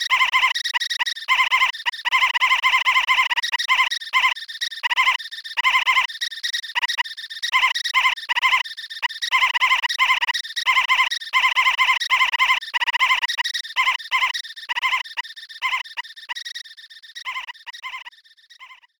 Voice clip from Tetris & Dr. Mario
T&DM_Virus_Voice_6.oga.mp3